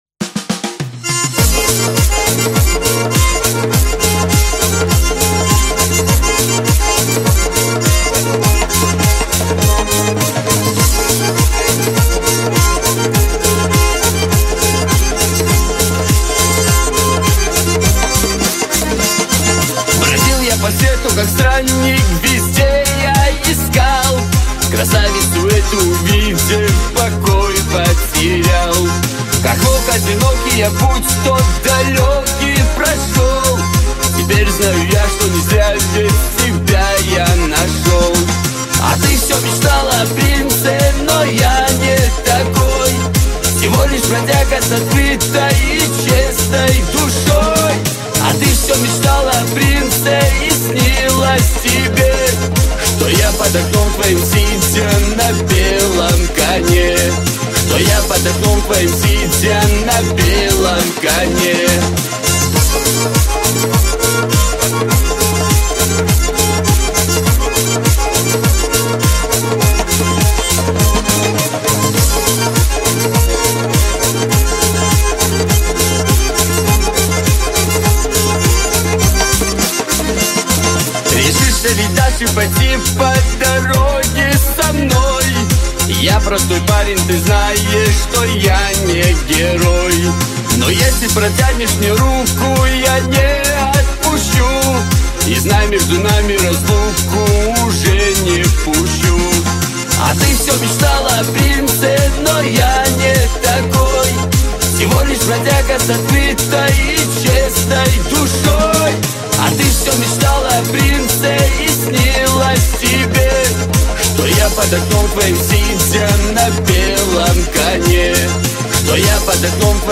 Кавказские песни